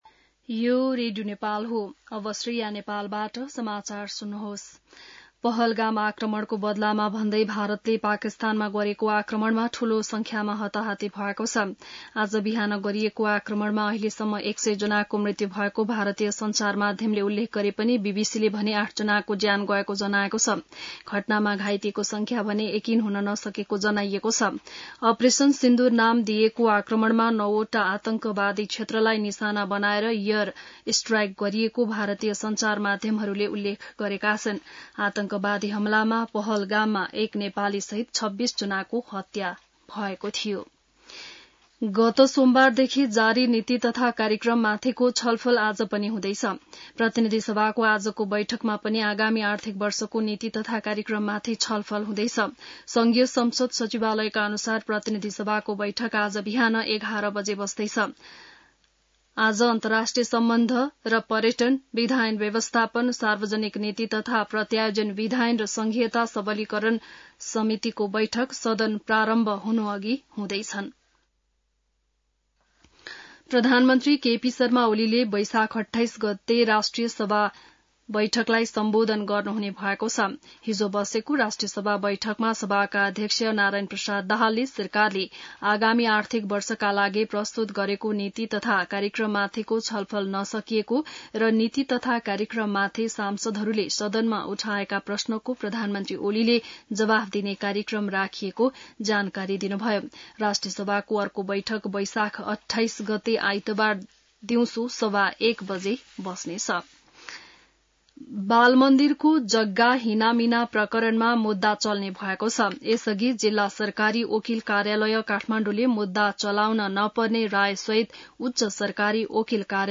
बिहान १० बजेको नेपाली समाचार : २४ वैशाख , २०८२